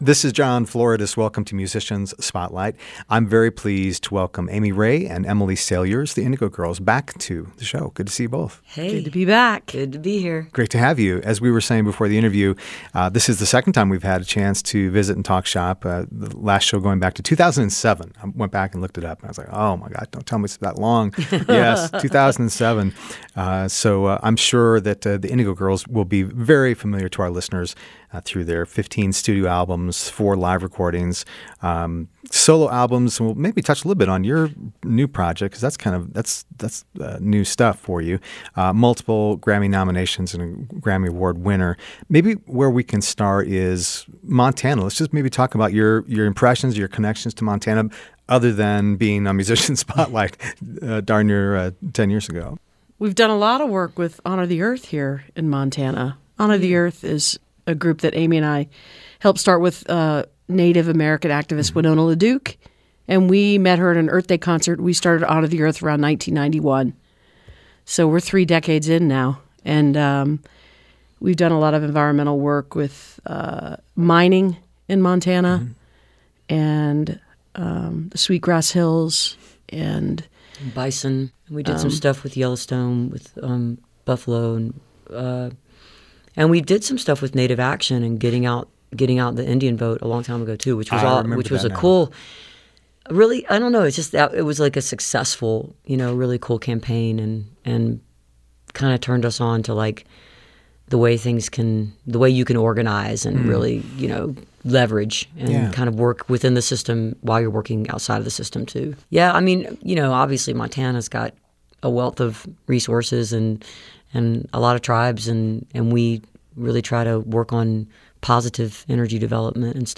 (recorded from webcast)
02. interview (4:39)